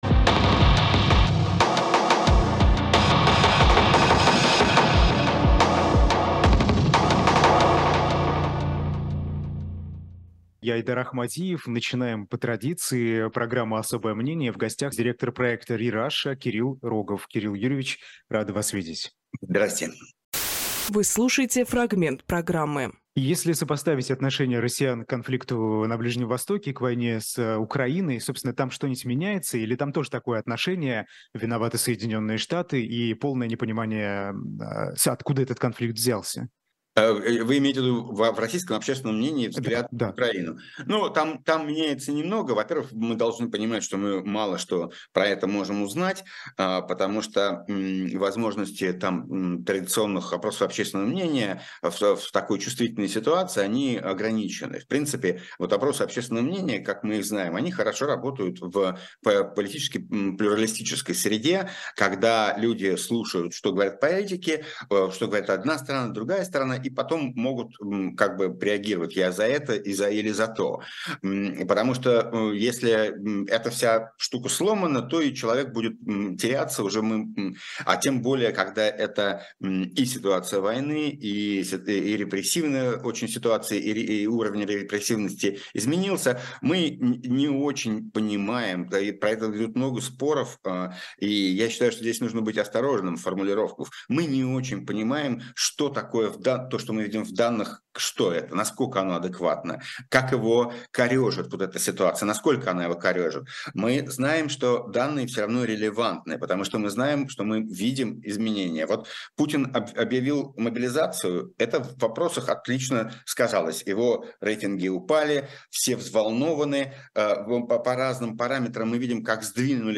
Фрагмент эфира от 31.10